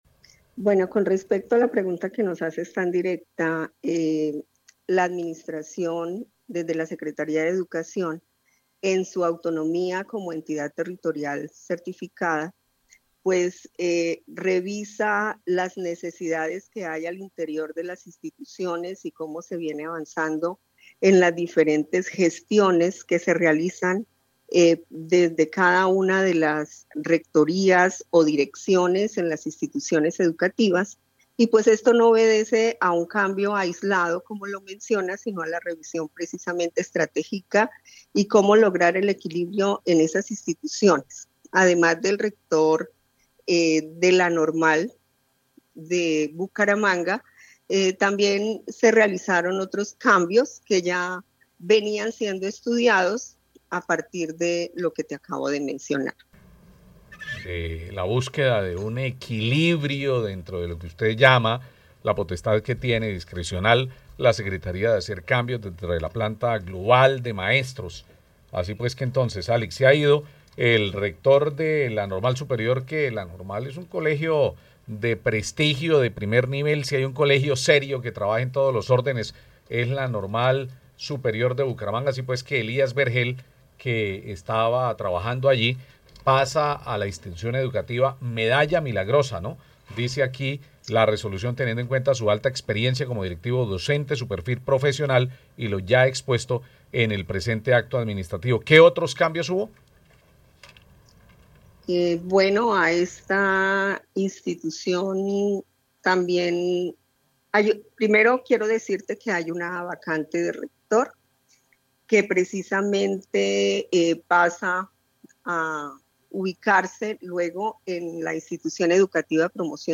Alix Chinchilla, secretaria de Educación de Bucaramanga